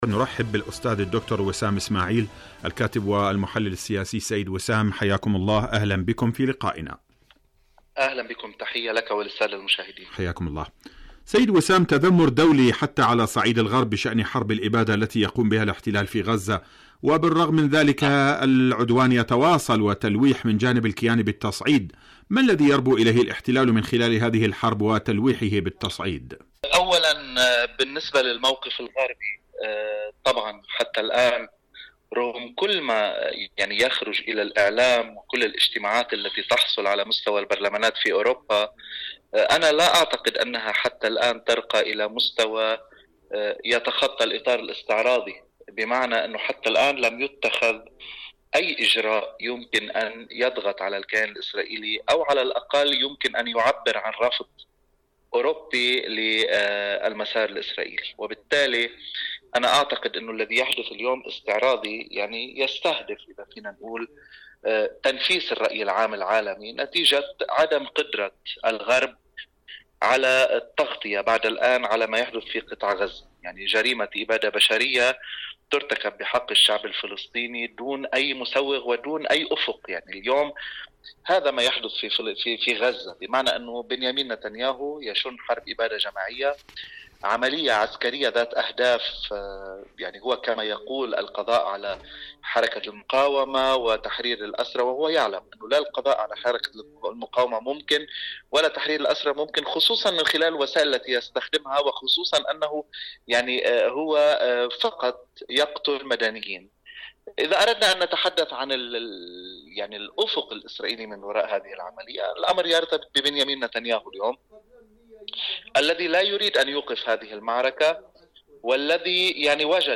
مقابلات إذاعية